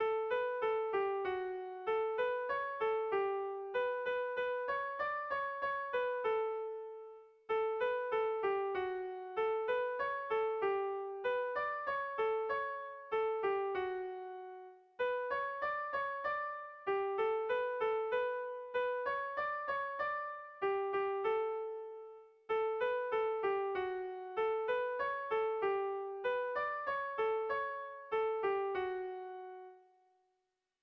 Irrizkoa
Zortziko handia (hg) / Lau puntuko handia (ip)
A1A2BA2